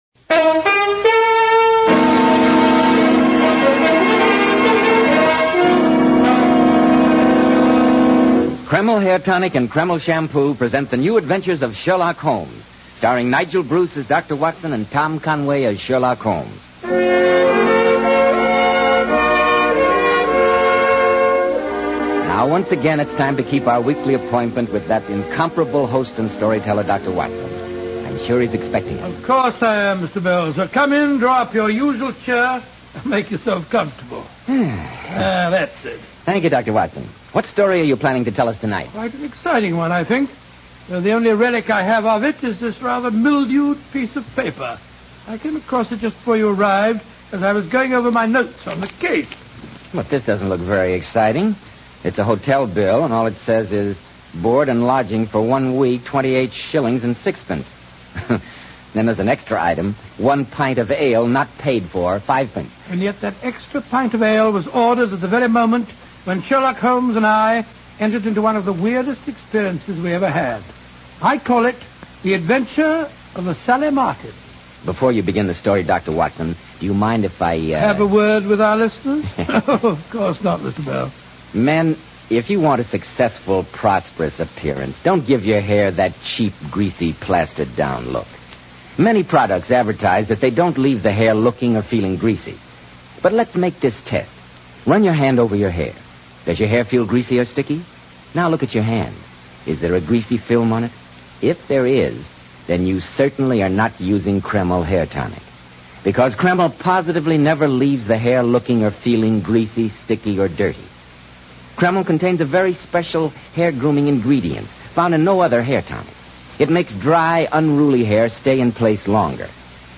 Radio Show Drama with Sherlock Holmes - The Sally Martin 1946